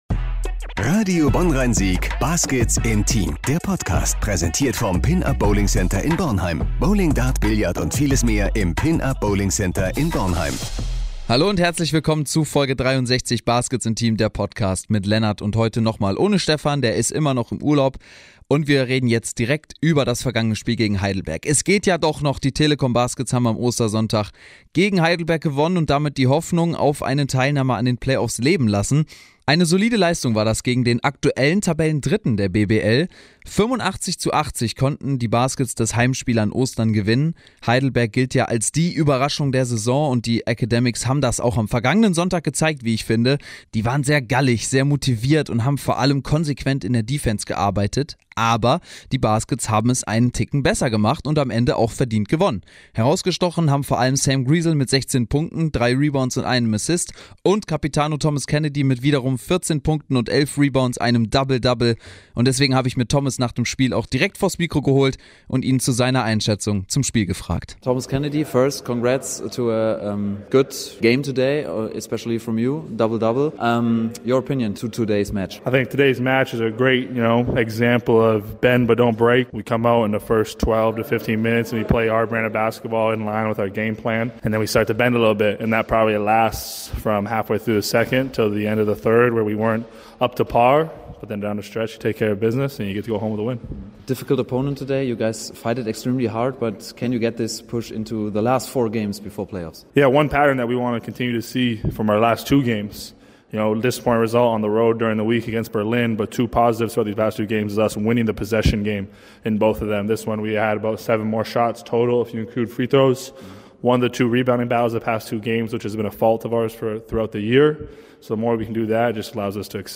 Außerdem gibts eine Umfrage von den Fans, die so gemischte Gefühle für den Endspurt der Saison haben. Und natürlich gibts noch den Ausblick auf die nächsten beiden Spiele, Würzburg und Braunschweig, die die Baskets auch gewinnen müssen, um Chancen auf die Play-Ins zu haben.